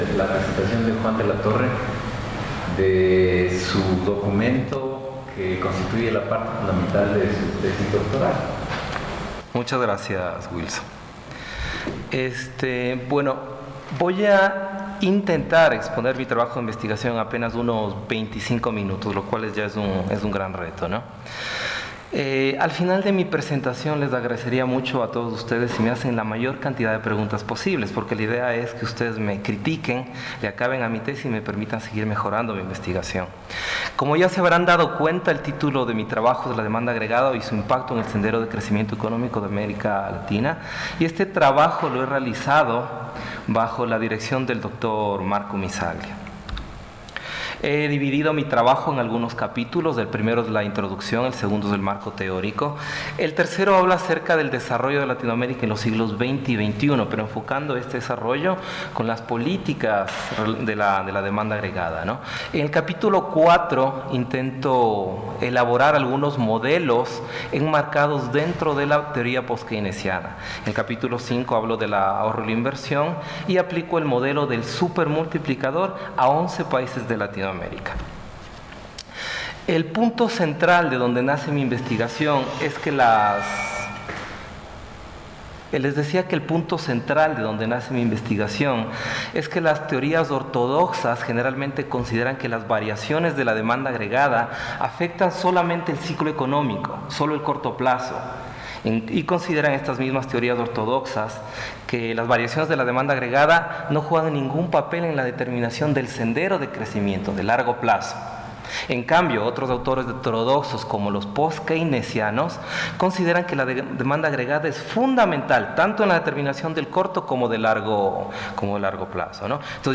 Coloquio